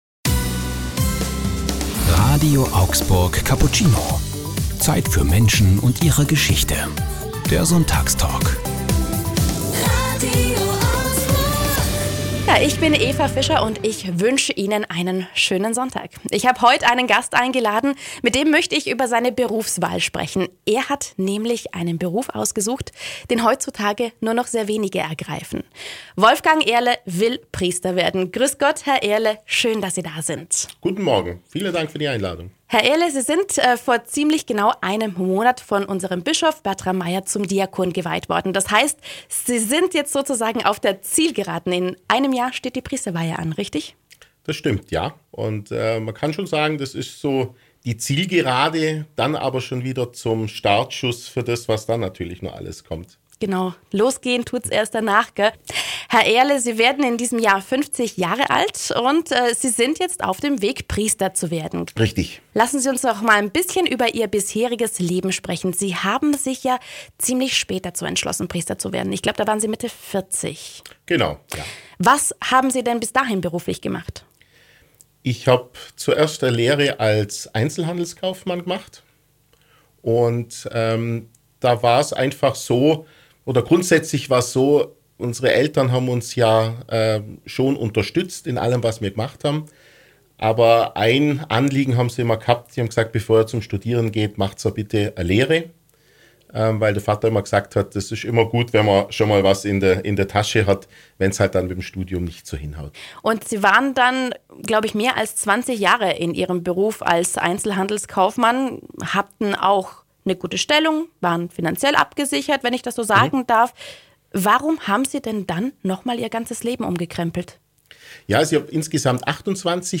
Warum will heutzutage noch jemand Priester werden, was sind die schönen Seiten an diesem Beruf. Wie geht man mit dem Stress um, wie mit Skandalen? Über all diese Themen sprechen wir in unserem Sonntagstalk.